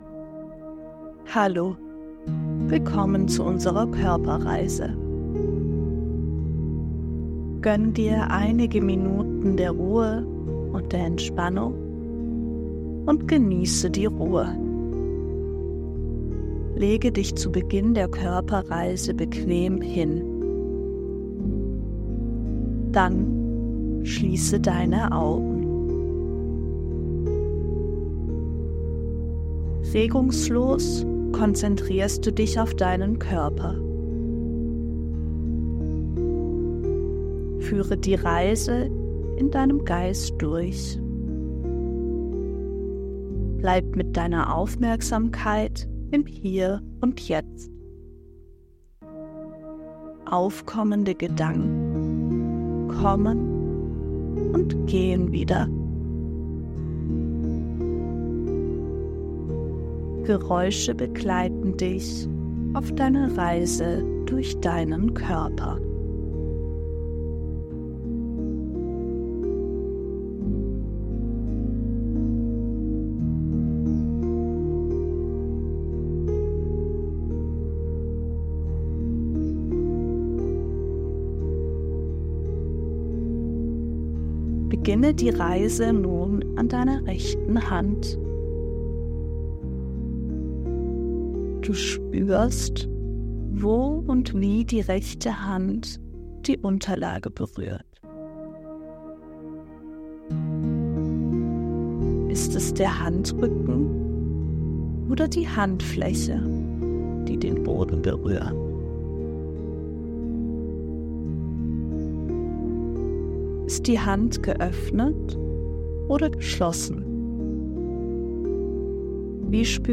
Drücken Sie auf den Button und begeben Sie sich auf eine entspannende Körperreise.
Kleinundgroß_Körperreise.mp3